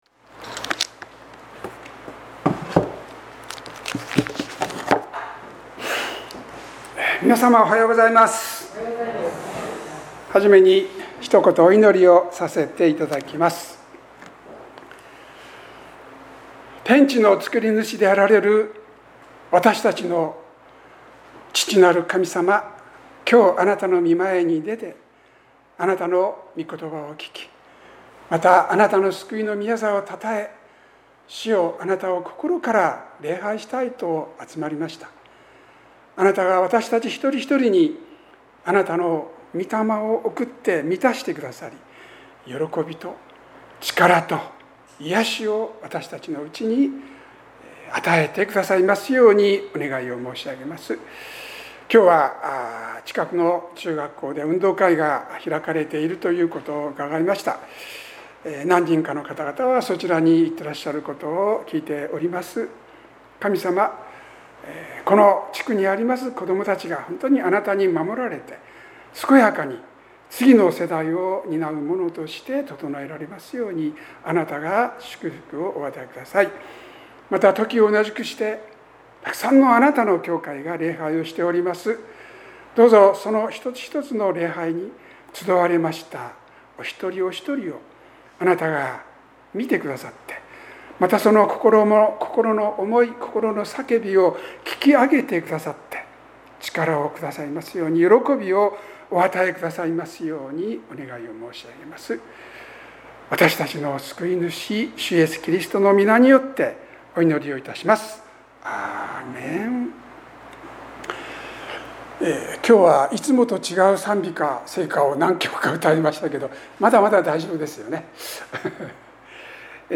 ここには礼拝の録音メッセージが収録されています。